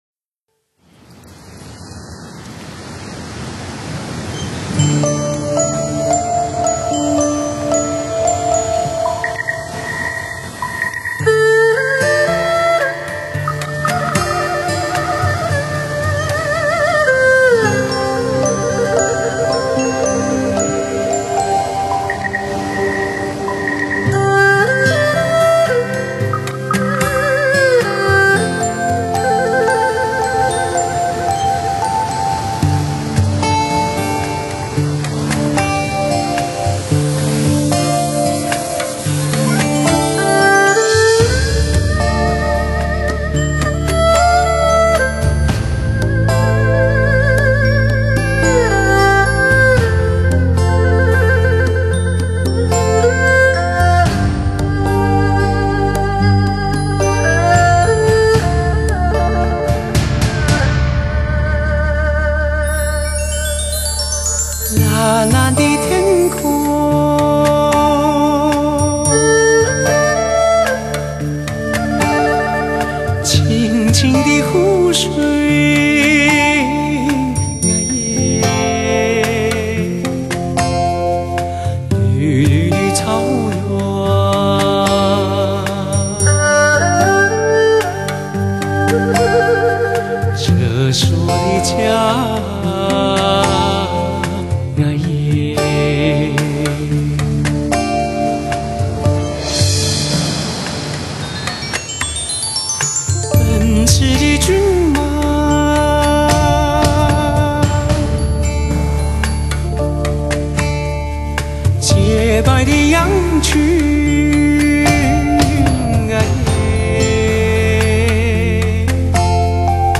示范男声